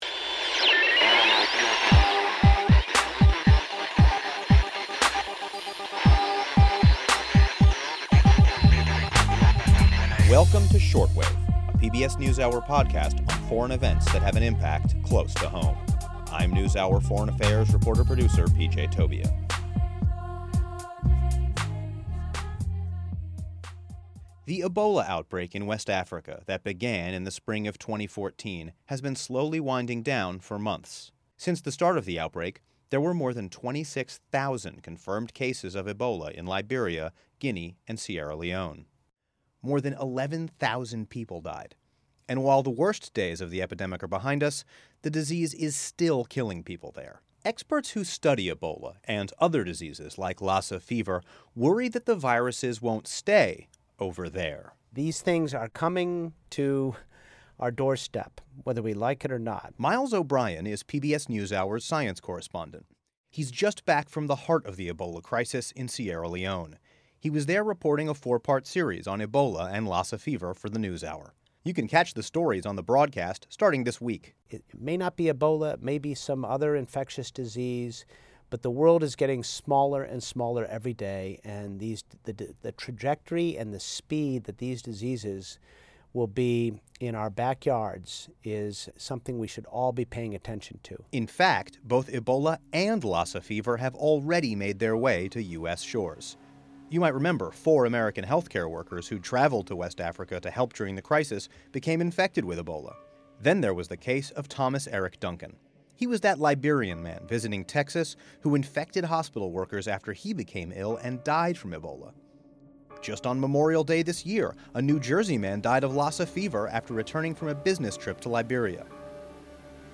For this week's Shortwave podcast, we interview PBS NewsHour Science correspondent Miles O'Brien about his trip to the heart of the Ebola outbreak in Sierra Leone. He discusses the scientists who study and track the disease and the critical role that social media can play in containing an outbreak.